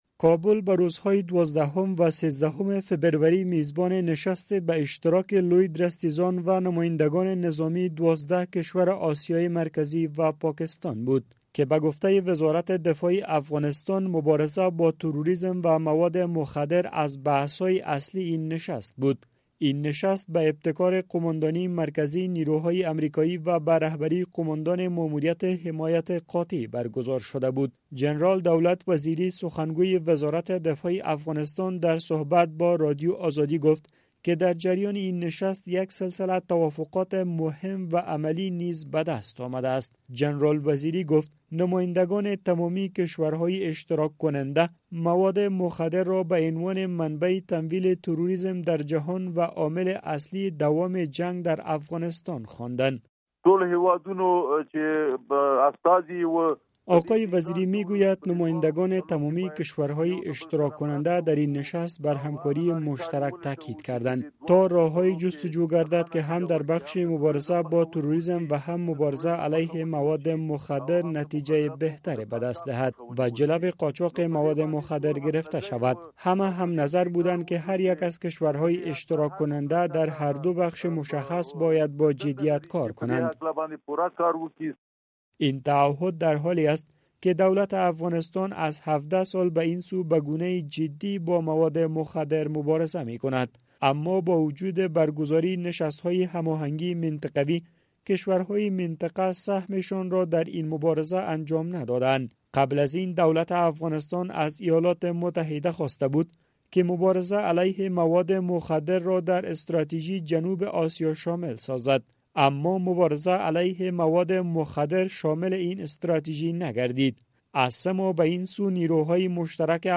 میرزا محمد یارمند معین پیشین وزارت امور داخله افغانستان
گزارش